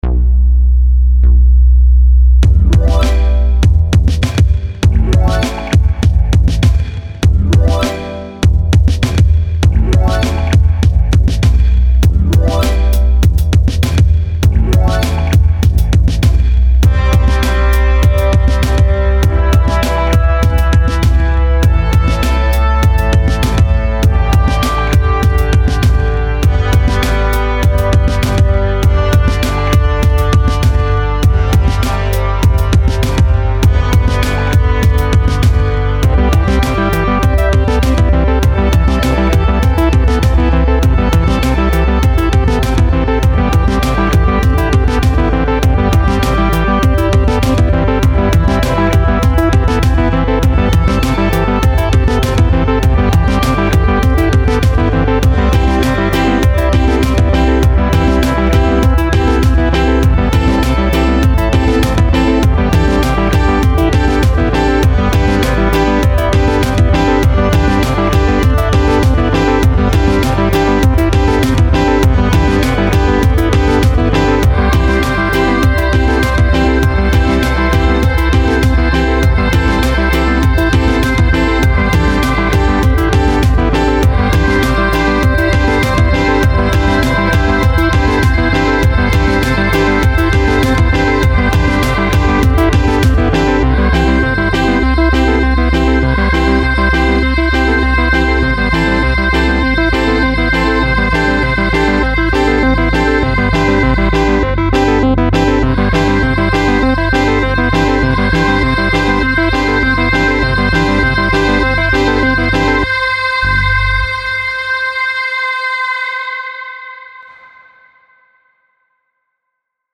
08 Medieval Disco.mp3